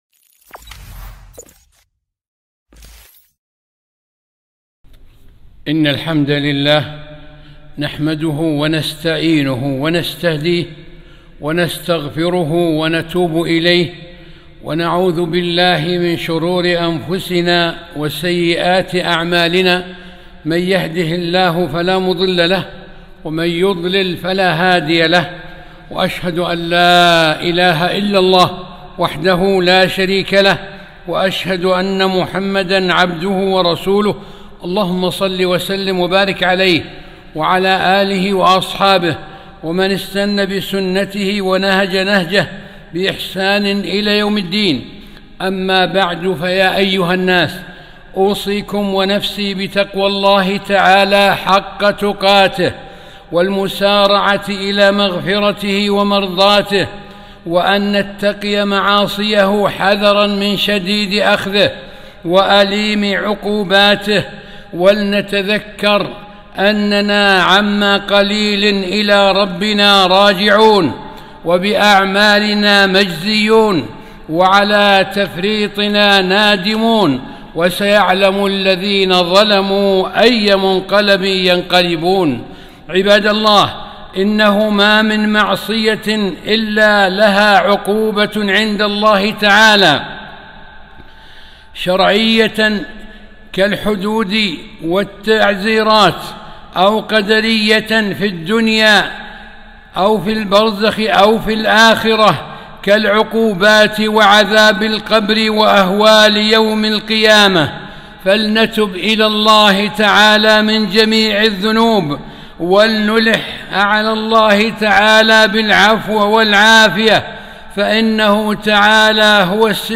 خطبة - التحذير من المعصية والمجاهرة بها